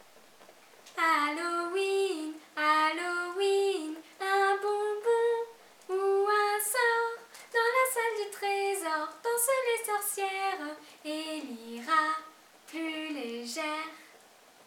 DSC_0067-Chanson-2-FRA.mp3